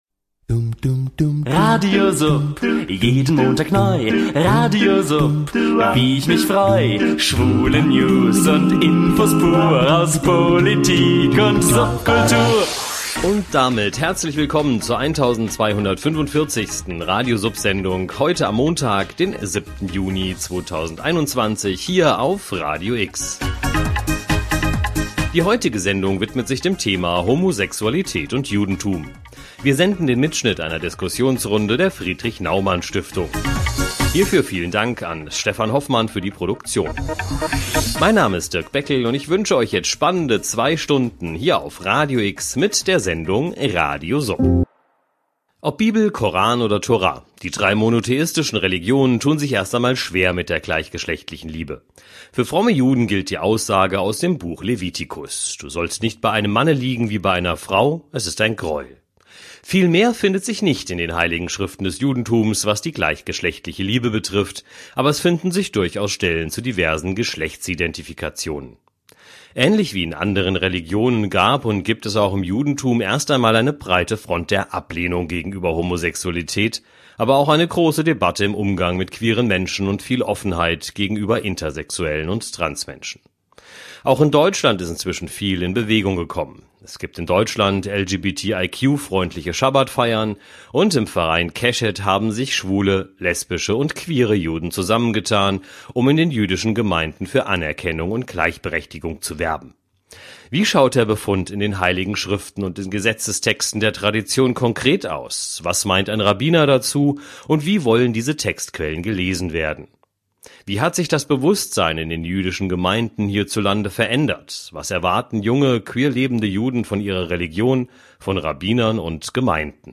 Eine Veranstaltung der Friedrich-Naumann-Stiftung vom 10.Mai 2021, die wir mit freundlicher Genehmigung ausstrahlen durften